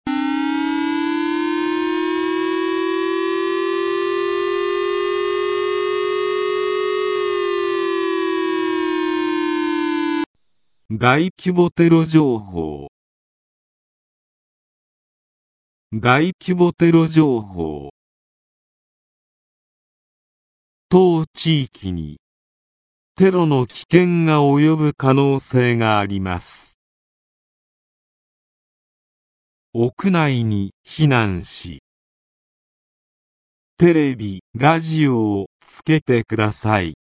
全国瞬時警報システム（J－ALERT）による放送例
武力攻撃等に関する情報の通報（例）